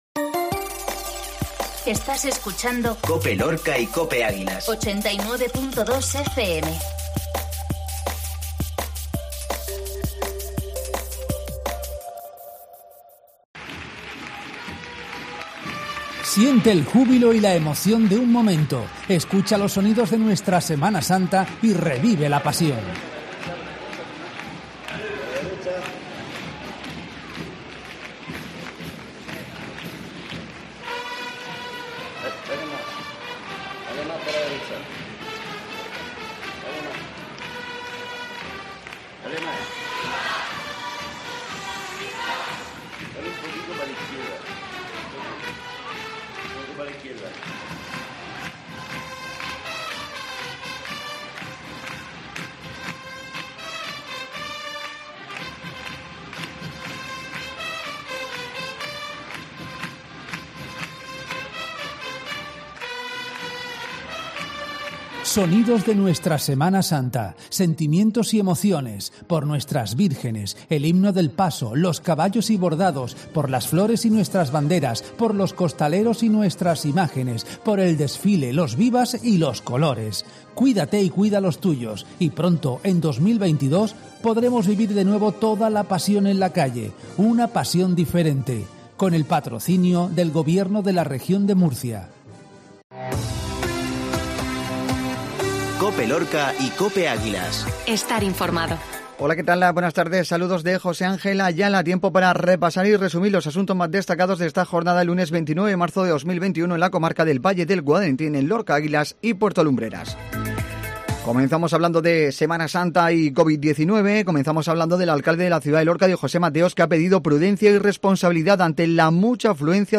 INFORMATIVO MEDIODÍA COPE LUNES